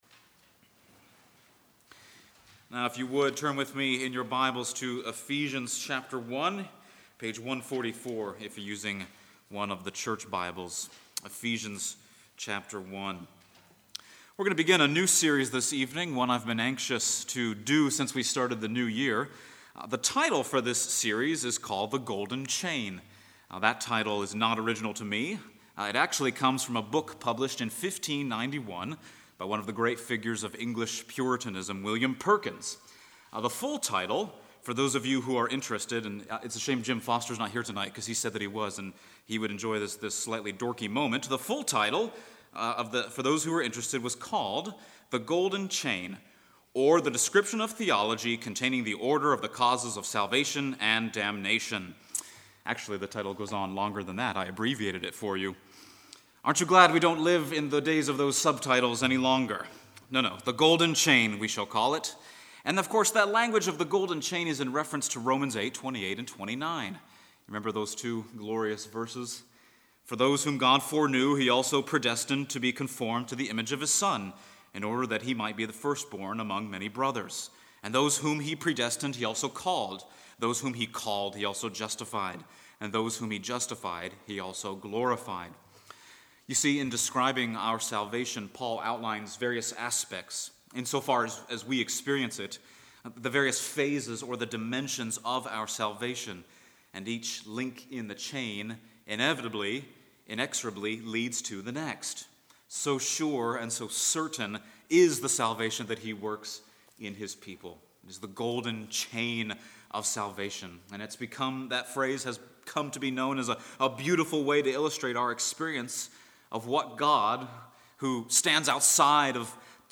Passage: Ephesians 1:3-14 Service Type: Sunday Evening